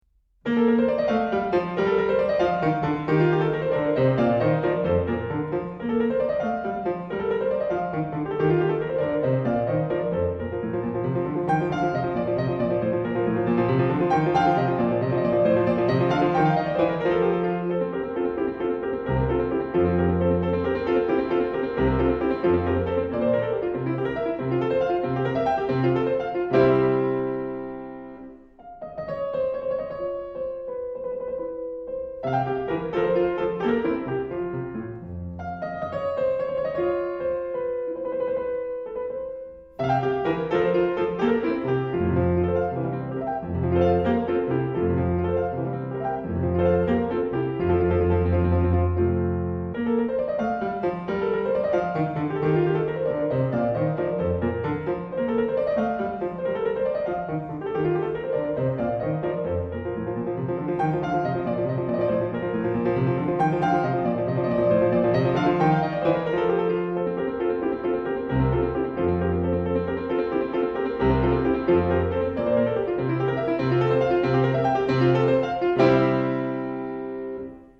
Valladolid (Spain), Auditorium Miguel Delibes